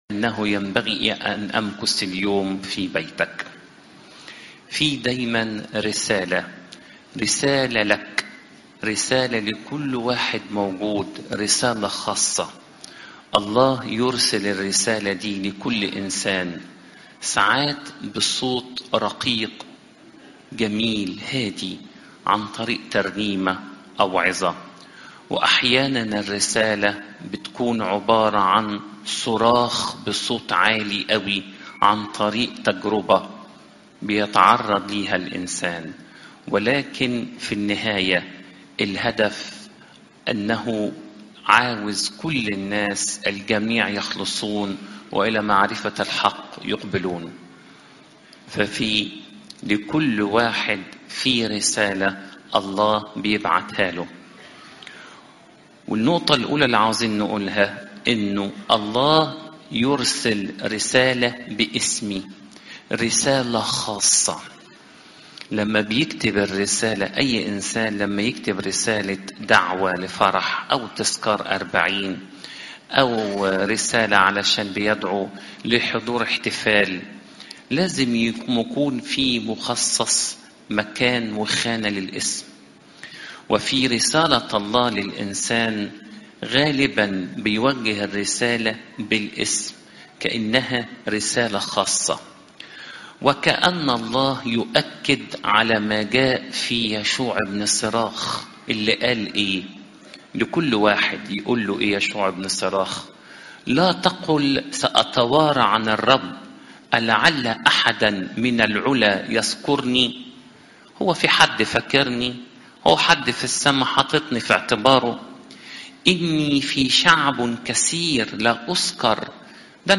عظات المناسبات